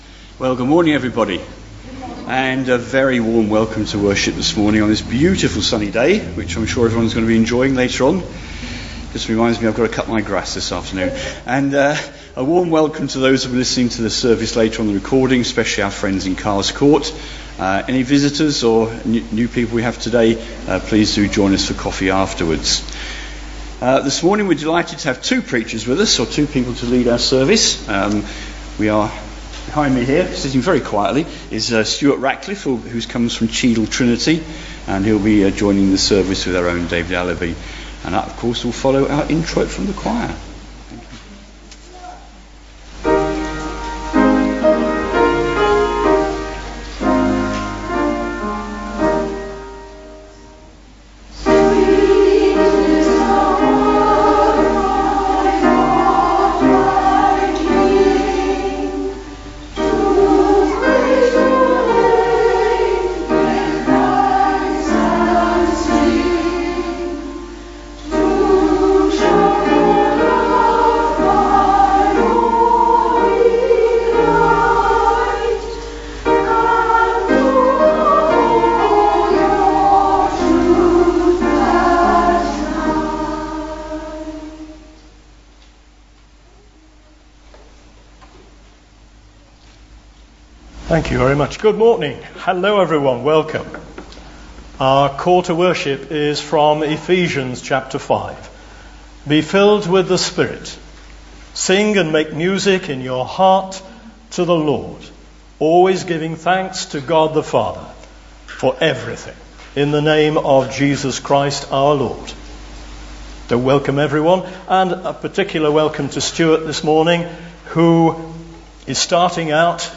Morning Worship
Genre: Speech.